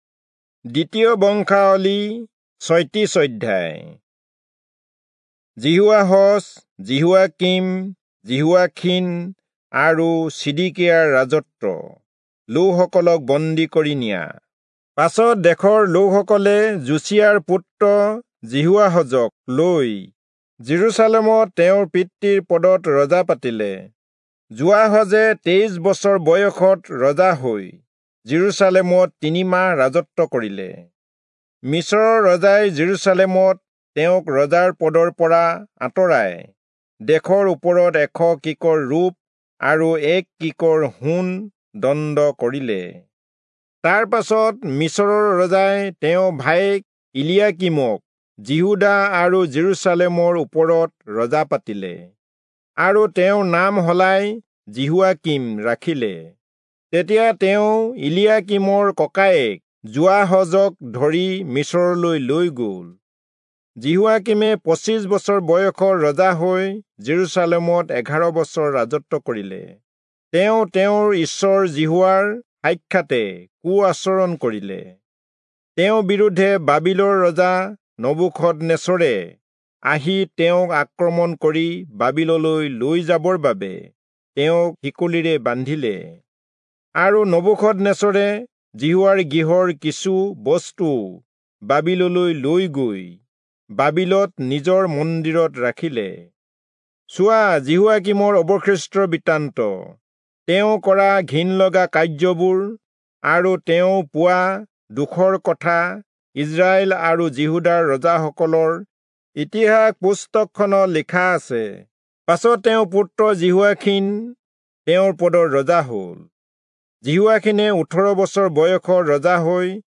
Assamese Audio Bible - 2-Chronicles 10 in Orv bible version